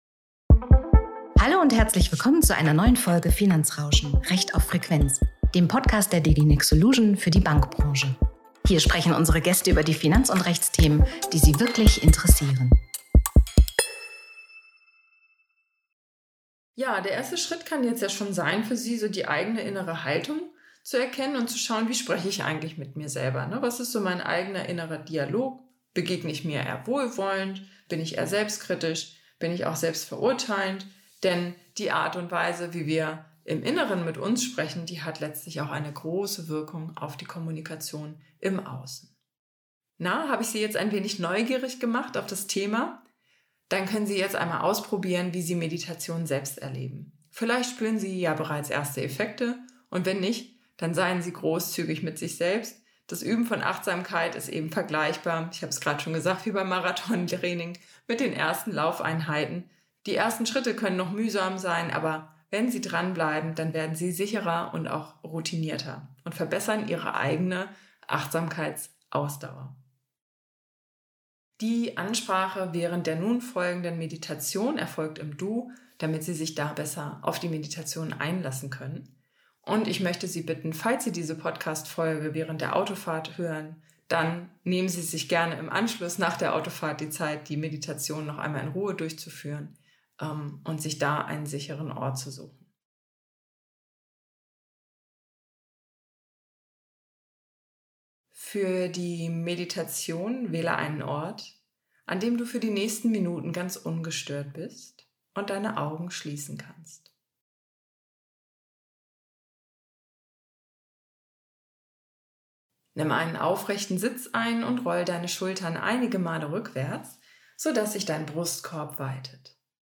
Sie können in der nachfolgenden Podcast-Folge eine Meditationseinheit für Ihre eigenen Achtsamkeit abrufen.